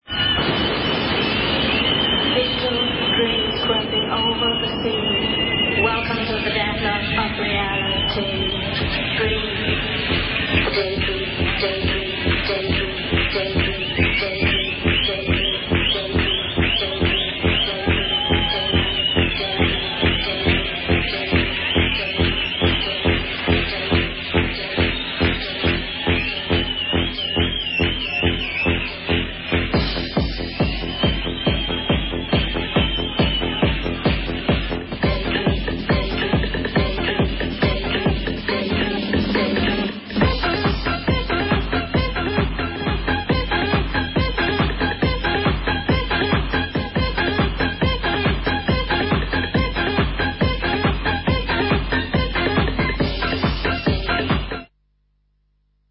I need help with an unknown trance track from 1998,